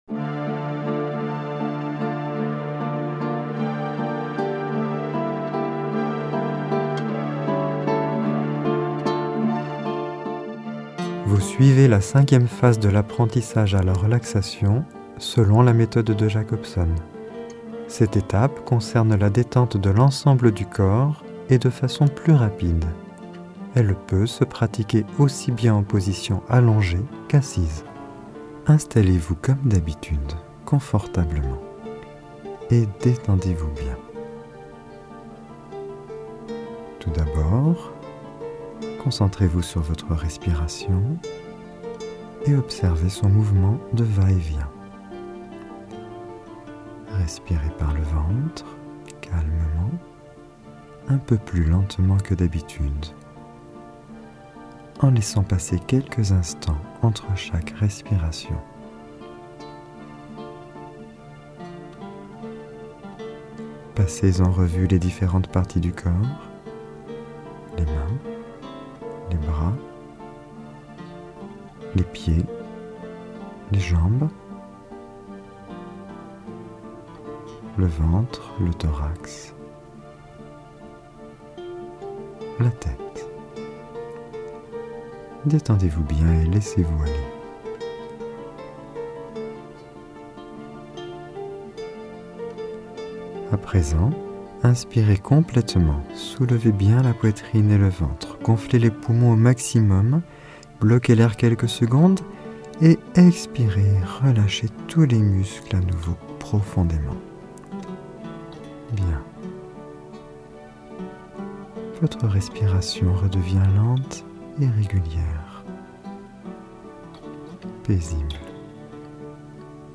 Genre : Meditative.
15-Cinquieme-etape-_-relaxation-globale-rapide-1.mp3